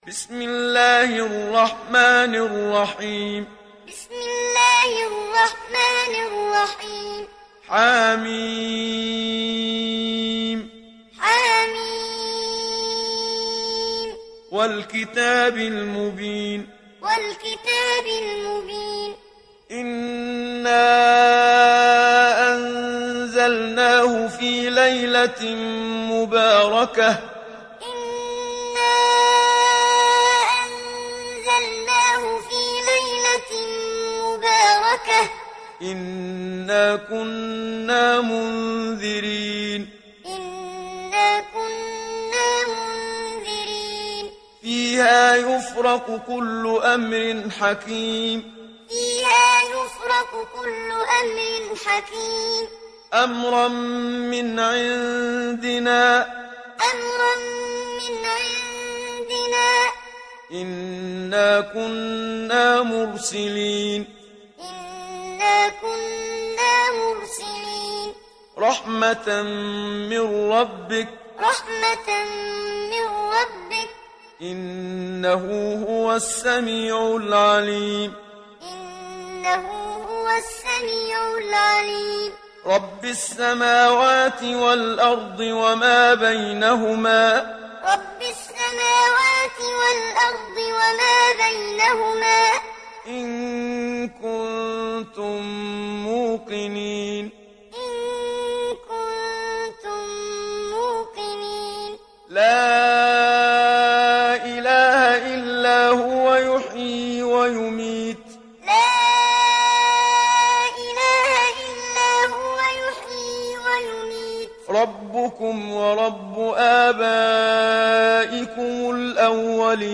Duhan Suresi İndir mp3 Muhammad Siddiq Minshawi Muallim Riwayat Hafs an Asim, Kurani indirin ve mp3 tam doğrudan bağlantılar dinle
İndir Duhan Suresi Muhammad Siddiq Minshawi Muallim